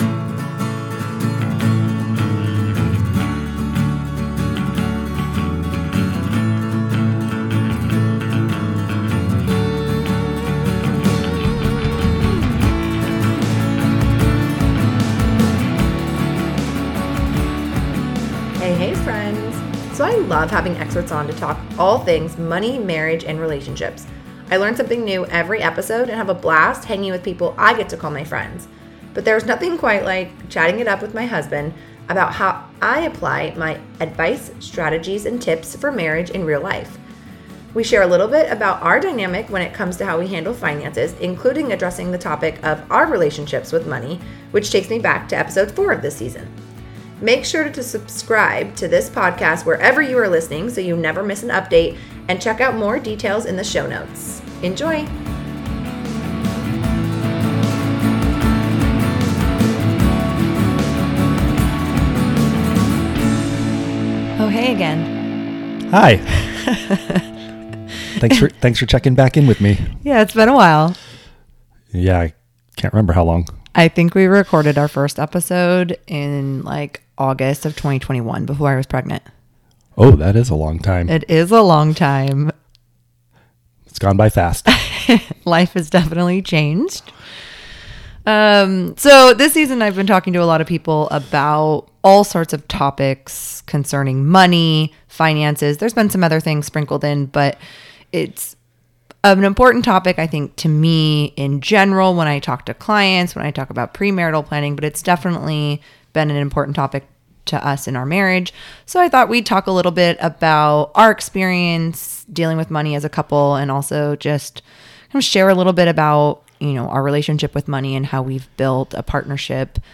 But there is nothing quite like chatting it up with my Husband about how I apply my advice, strategies and tips for marriage in real life.